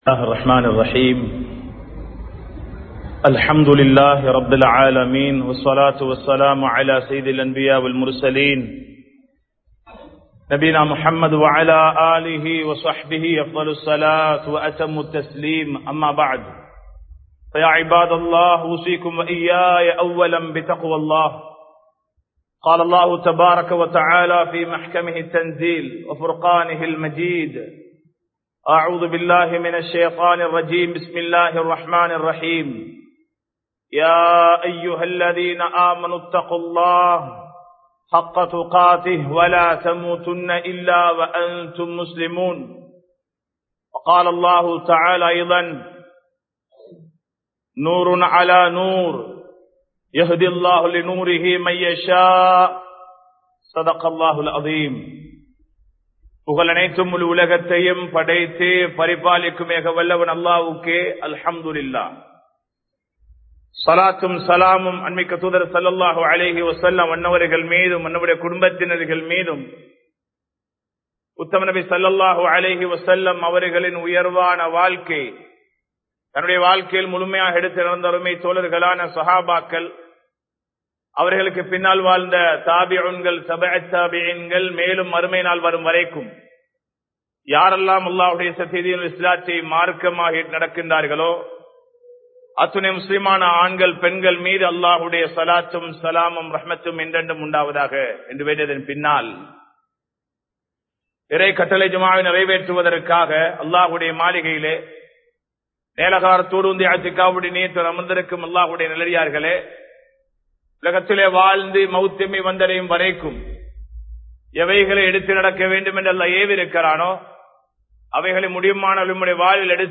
மற்றவர்களை ஏமாற்றாதீர்கள் | Audio Bayans | All Ceylon Muslim Youth Community | Addalaichenai